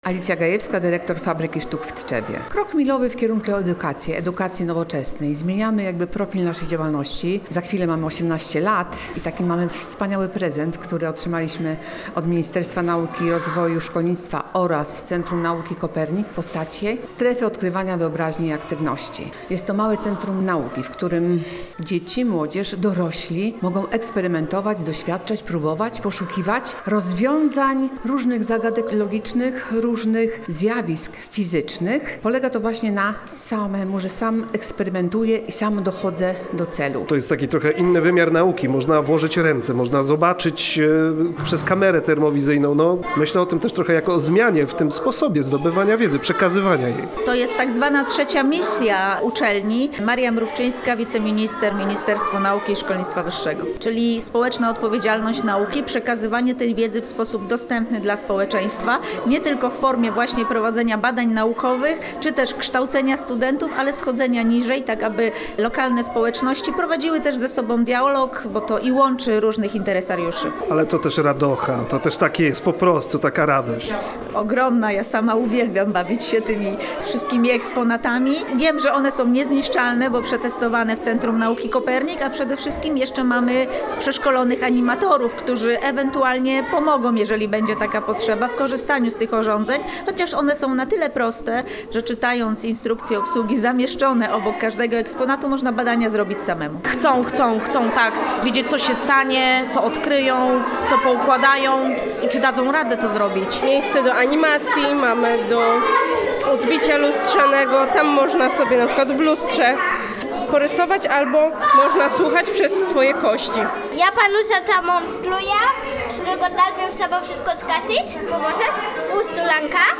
Głos trakcie otwarcia zabrała Maria Mrówczyńska, wiceminister w Ministerstwie Nauki i Szkolnictwa Wyższego.